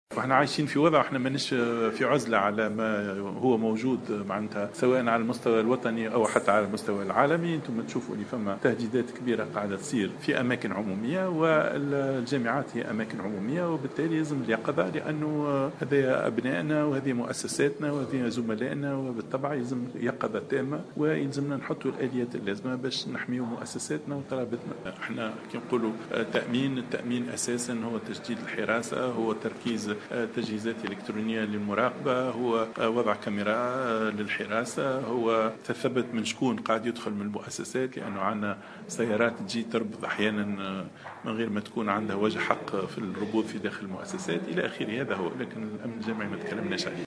أكد وزير التعليم العالي شهاب بودن خلال ندوة العمداء ومديري مؤسسات التعليم العالي والبحث العلمي التي احتضنتها ولاية المنستير اليوم الجمعة 20 نوفمبر 2015 أنه سيتم حماية و تأمين مؤسسات التعليم العالي بوصفها أماكن عمومية.